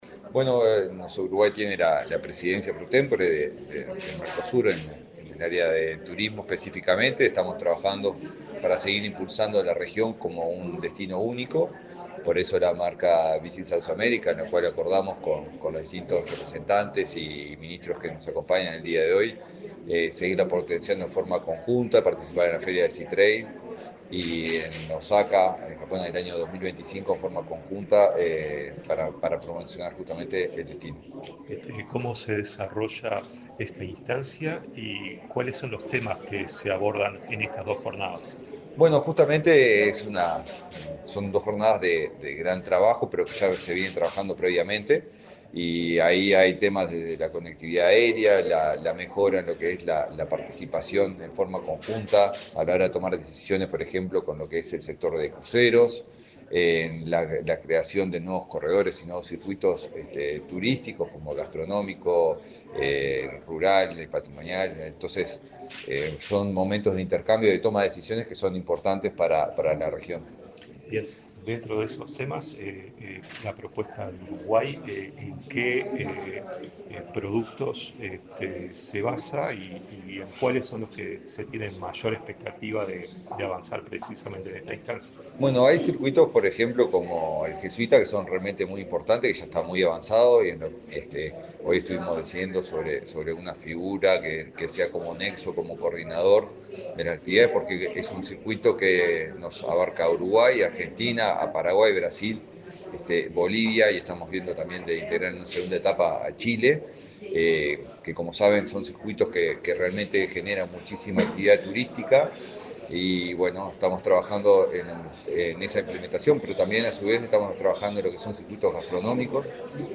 Declaraciones del ministro de Turismo, Eduardo Sanguinetti
Declaraciones del ministro de Turismo, Eduardo Sanguinetti 29/11/2024 Compartir Facebook X Copiar enlace WhatsApp LinkedIn El ministro de Turismo, Eduardo Sanguinetti, dialogó con Comunicación Presidencial, este viernes 29 en Montevideo, durante la LXXV Reunión Especializada de Turismo del Mercosur y XXXI Reunión de Ministros de Turismo del Mercosur.